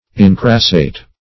Incrassate \In*cras"sate\, v. t. [imp.